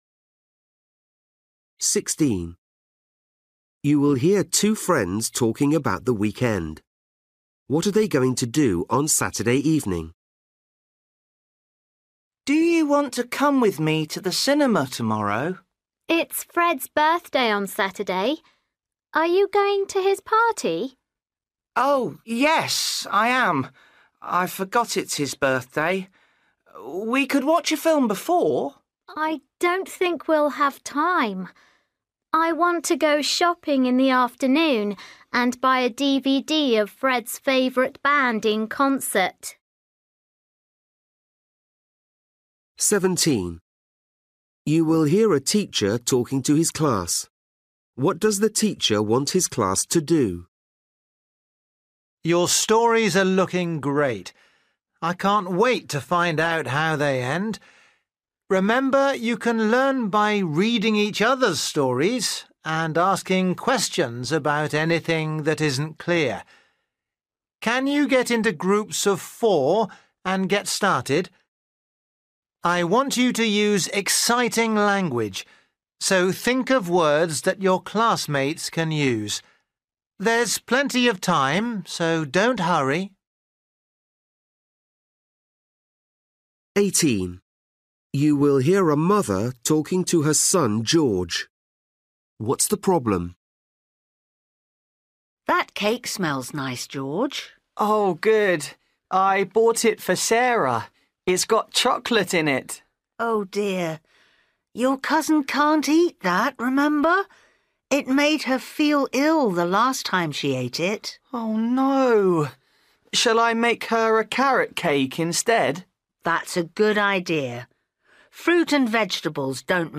Listening: everyday short conversations
16   You will hear two friends talking about the weekend. What are they going to do on Saturday evening?
17   You will hear a teacher talking to his class. What does the teacher want his class to do?
20   You will hear a girl talking about a programme she watched. Why did she like it?